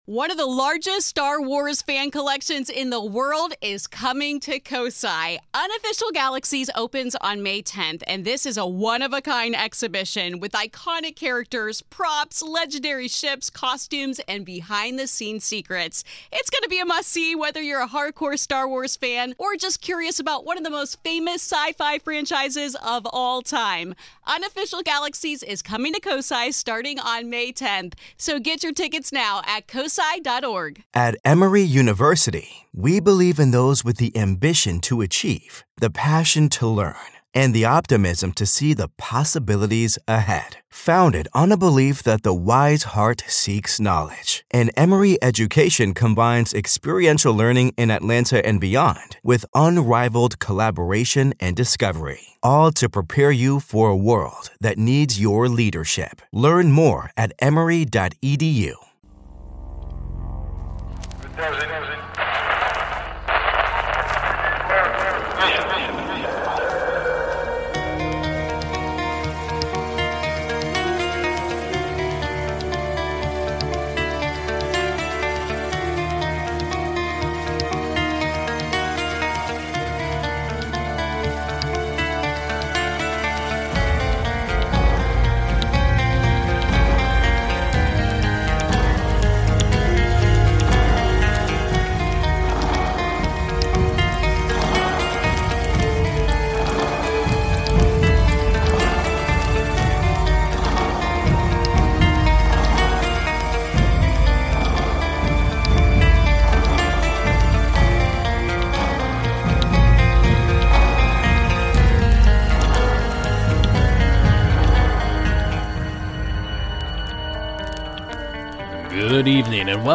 Witnesses of bigfoot, sasquatch, ufo's, aliens, ghosts and an array of other creatures from the paranormal and cryptozoology realm detail their encounters.